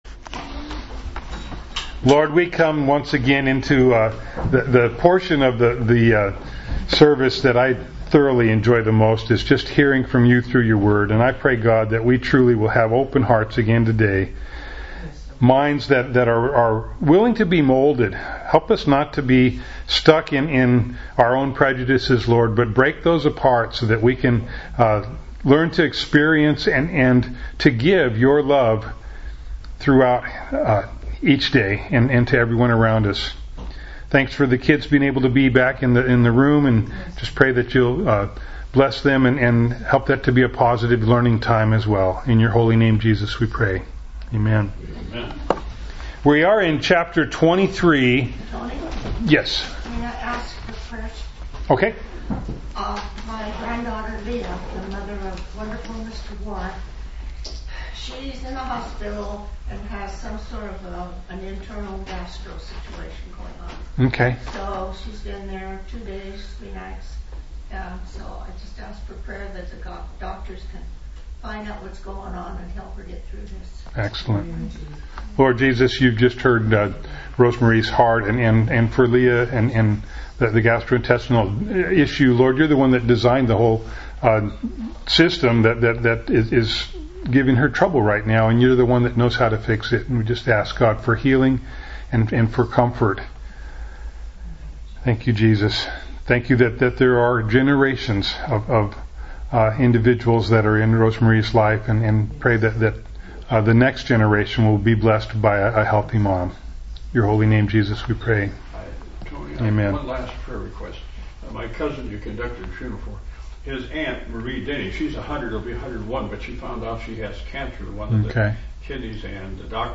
Acts 23:1-35 Service Type: Sunday Morning Bible Text